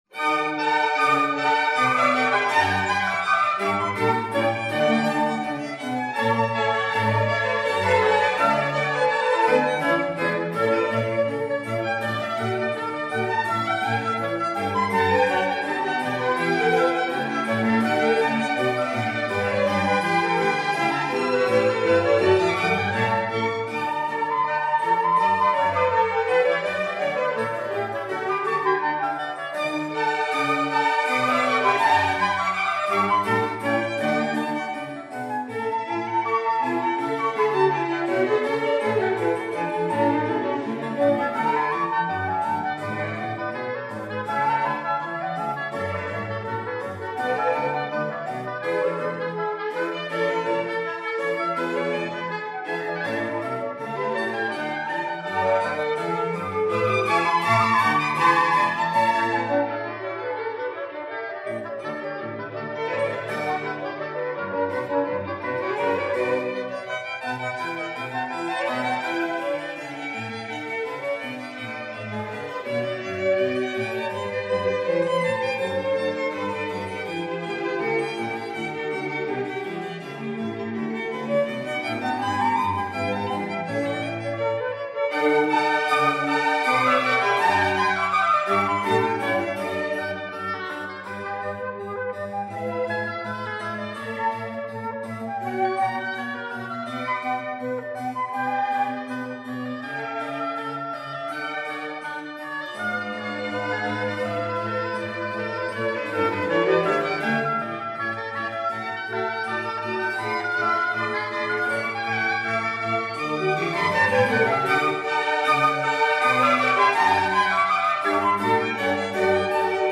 Concerto No. 3 in D Major for harpsichord and strings, BWV 1054 (Allegro) – Adagio e piano sempre – Allegro 3.
Triple Concerto in D Minor for flute, oboe, violin & strings (from BWV 1064) Allegro – Adagio – Allegro Total playing time 79:54
Concerto for oboe and strings in F Major (from BWV 1053) Allegro – Siciliano – Allegro 2.